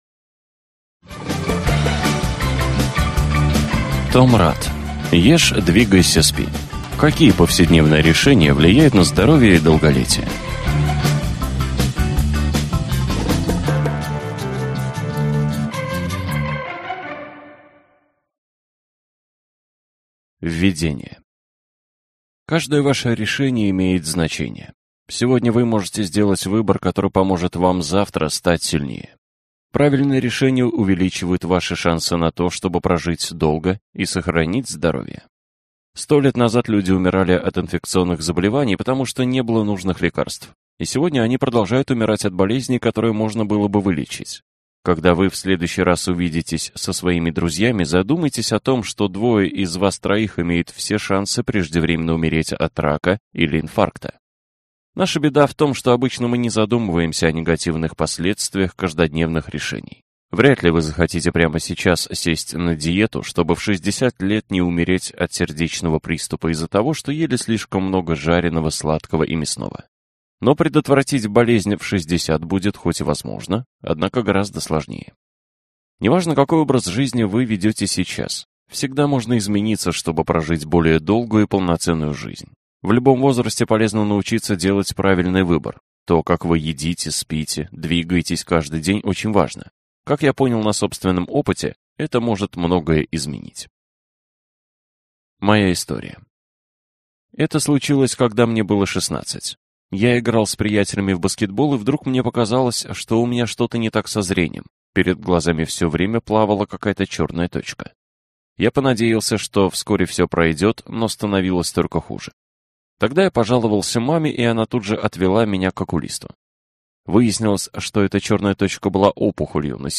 Аудиокнига Ешь, двигайся, спи. Как повседневные решения влияют на здоровье и долголетие | Библиотека аудиокниг